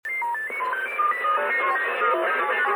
Непонятный сигнал на 27.245 МГц
pomeha27.mp3